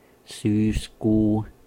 Ääntäminen
Ääntäminen Tuntematon aksentti: IPA: /ˈsyːsˌkuu/ Haettu sana löytyi näillä lähdekielillä: suomi Käännös Ääninäyte Erisnimet 1.